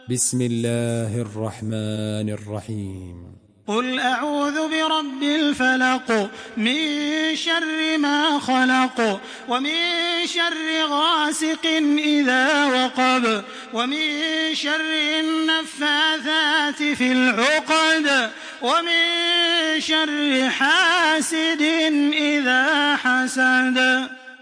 Surah Felak MP3 by Makkah Taraweeh 1426 in Hafs An Asim narration.
Murattal Hafs An Asim